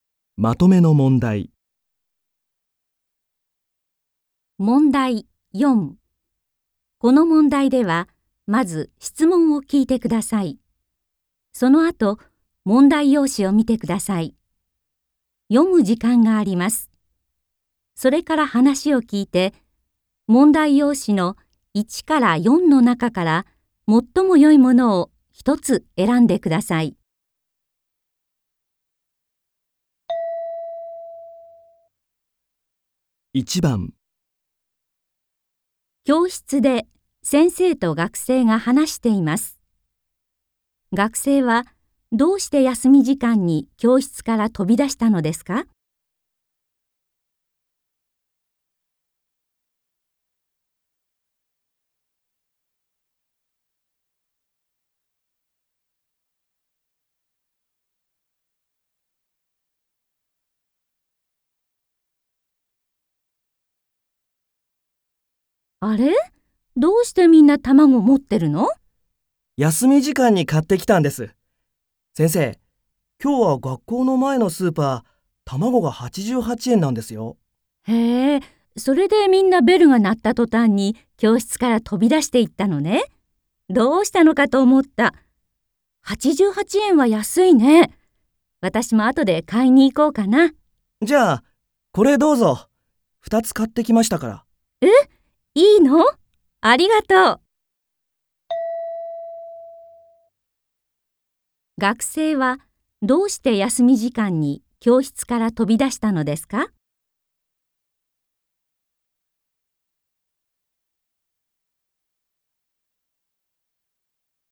問題 4 ［聴解］